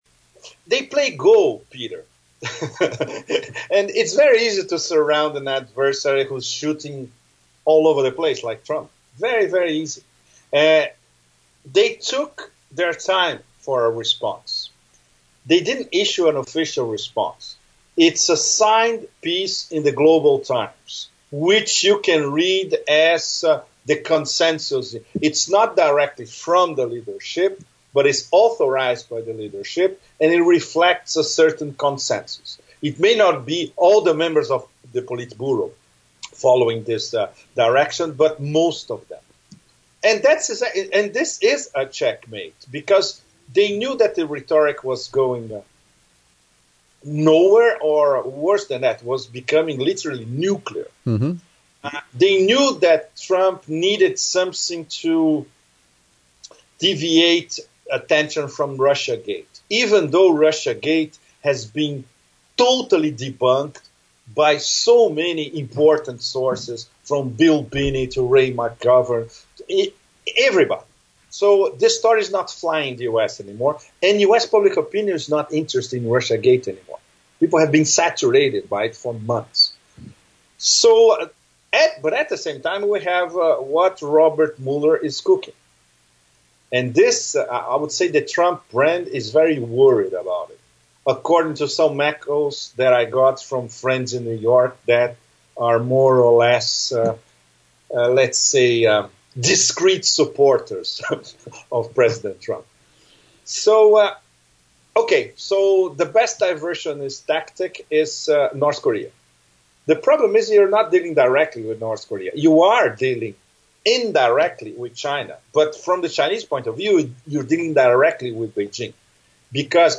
We also touch on Syria, Yemen, Saudi Arabia, and Brazil in this wide-ranging conversation.